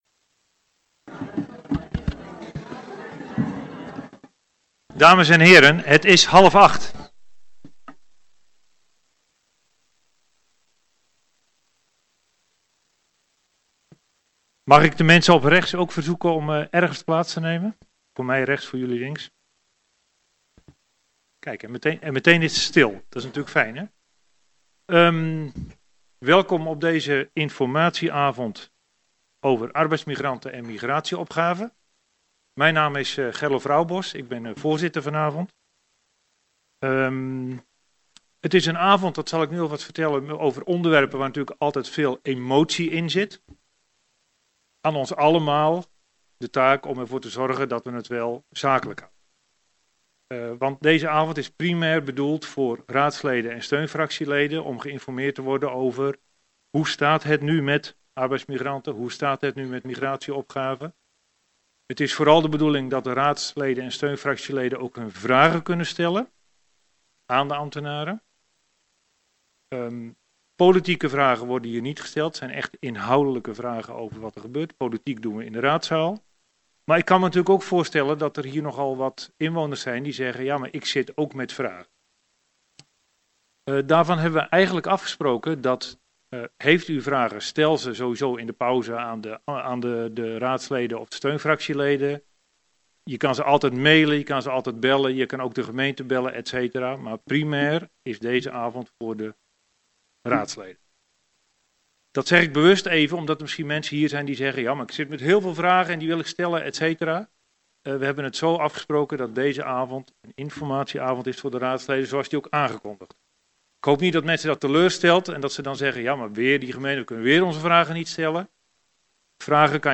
Locatie Raadzaal Boxtel Voorzitter Gerlof Roubos Toelichting Informatie-/uitwisselingsbijeenkomst Arbeidsmigranten en Migratieopgaven Deze bijeenkomst is primair bedoeld voor raads- en steunfractieleden.
Agenda documenten Agendabundel 14 MB Geluidsopnamen onderdeel Arbeidsmigranten 19 november 2024 23 MB Geluidsopnamen onderdeel Migratieopgave 19 november 2024 53 MB